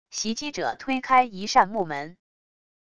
袭击者推开一扇木门wav音频